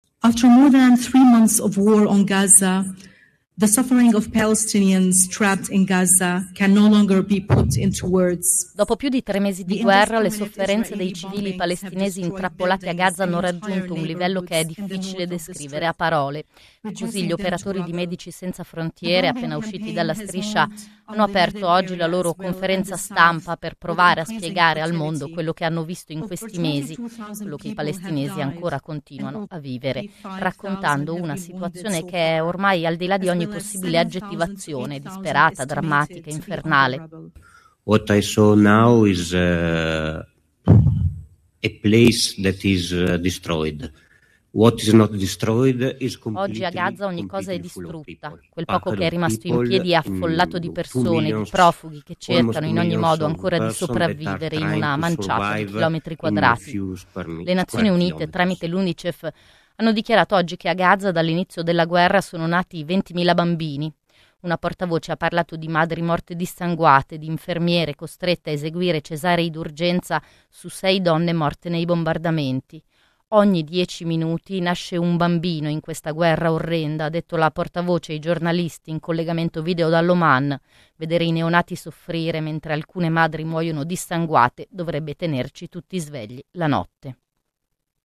A gaza però i bombardamenti continuano: solo nell’ultima giornata sono morte decine di persone, in bombardamenti hanno preso di mira i dintorni degli ospedali, in particolare nella zona centrale della Striscia. Il servizio.